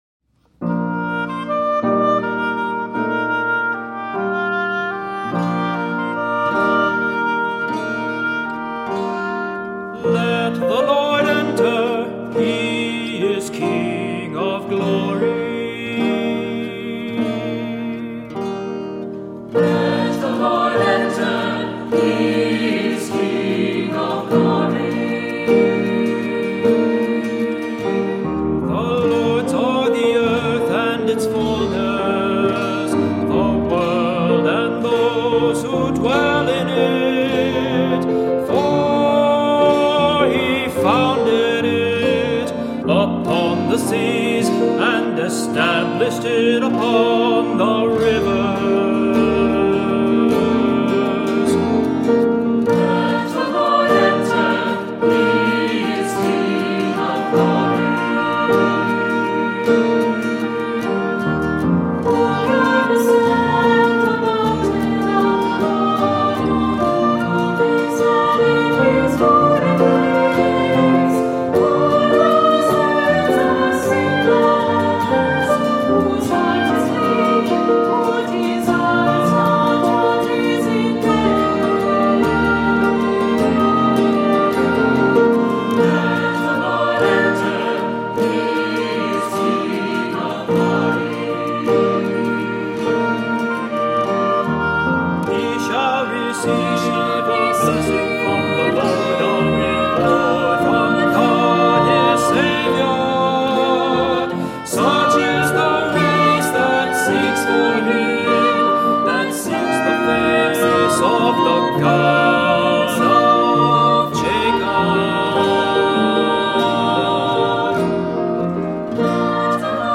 Voicing: SATB; Descant; Cantor; Assembly